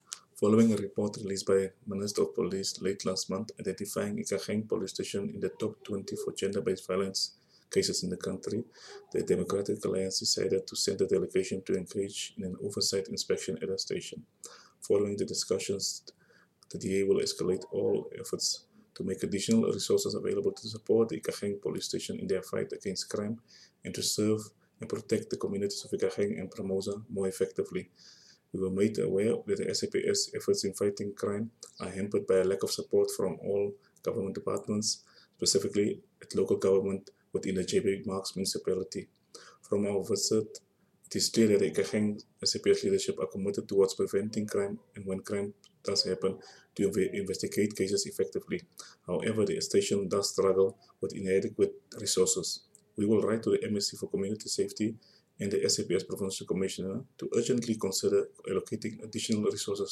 Note to Editors: Please find the attached soundbites in